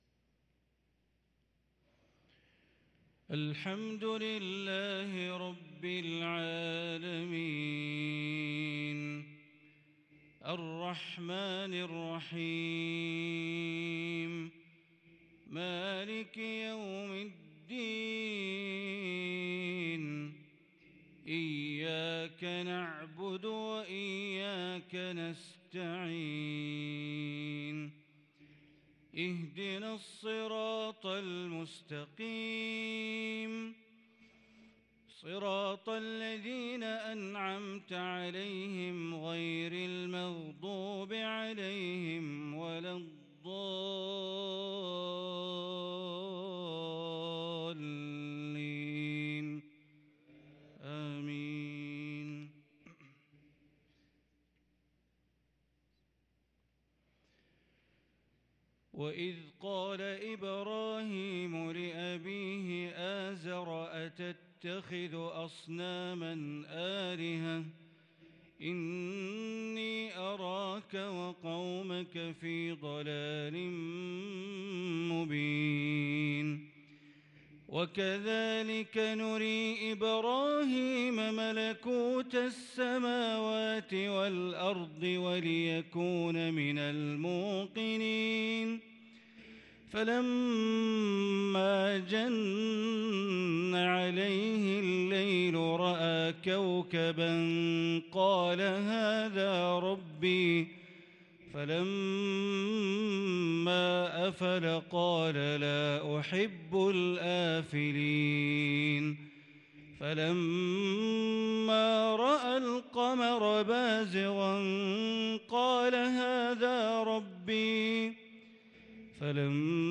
صلاة العشاء للقارئ بندر بليلة 18 ذو الحجة 1443 هـ
تِلَاوَات الْحَرَمَيْن .